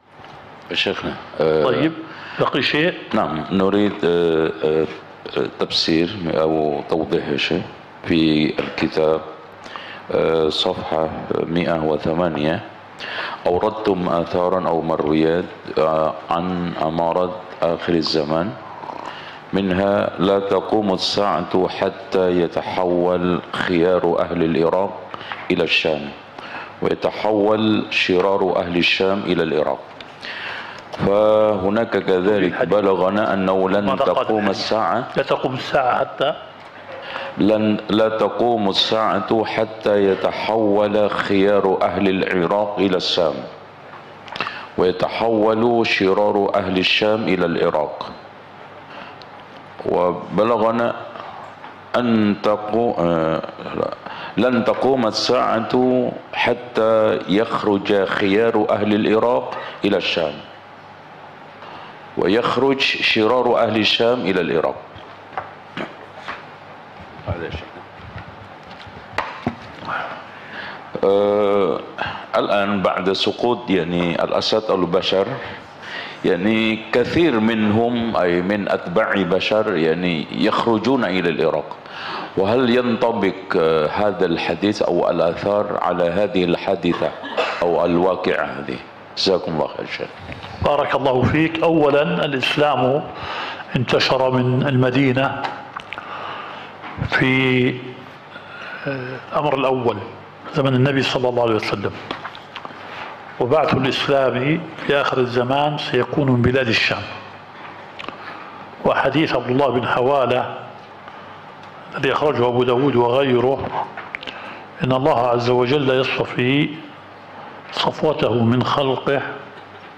الدورة الشرعية الثالثة للدعاة في اندونيسيا – منهج السلف في التعامل مع الفتن – المحاضرة الرابعة.